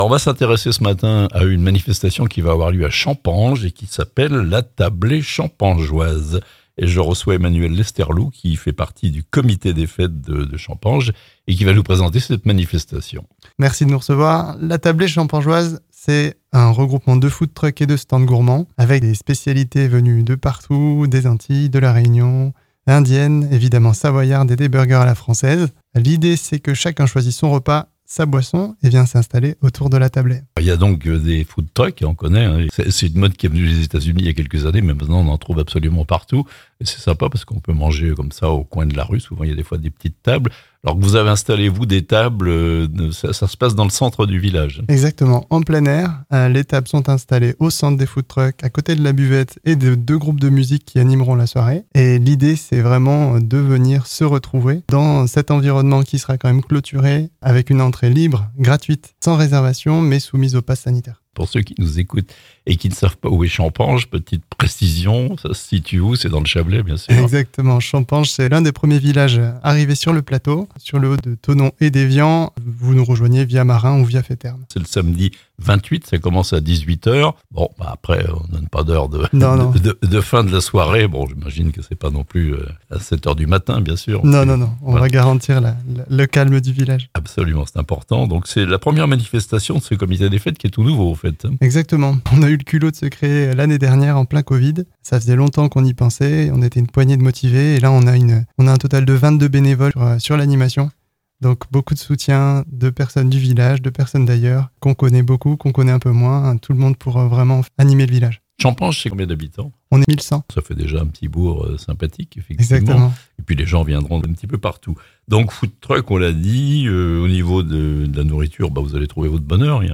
Des "food-trucks" pour faire la fête à Champanges (interview)